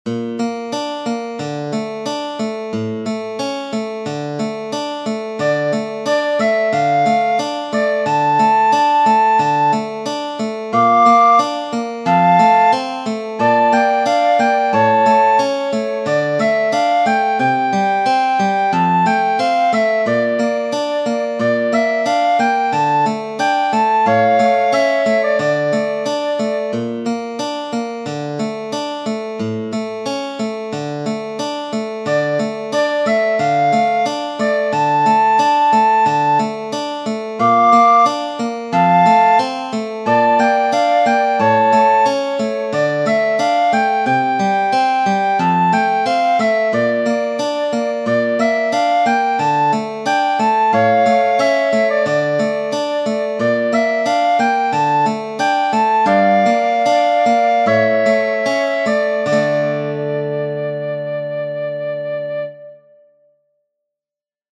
Tradizionale Genere: Religiose Entre le bœuf et l'âne gris dort, dort, dort le petit fils.